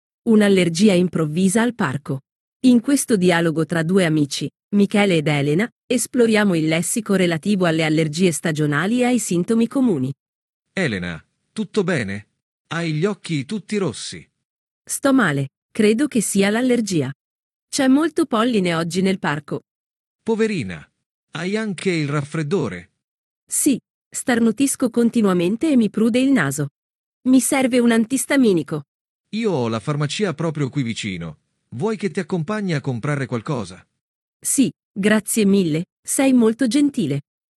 Dialogo 2